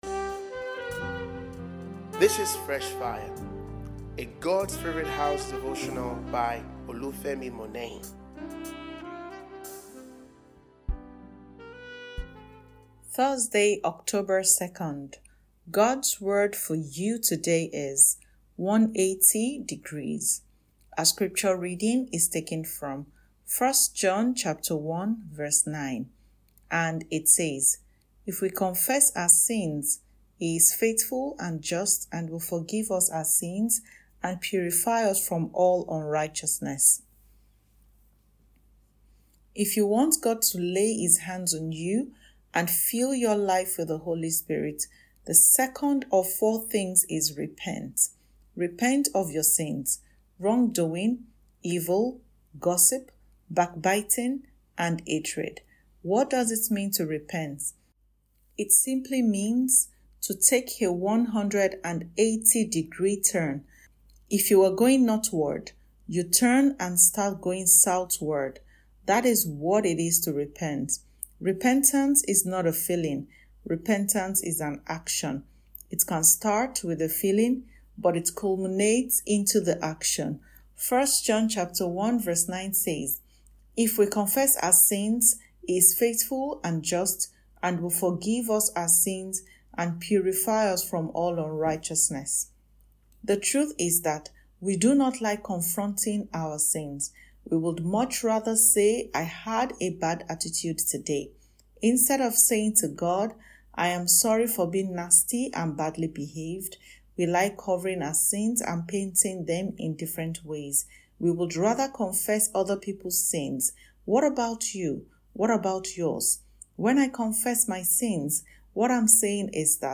» 180 Degrees Fresh Fire Devotional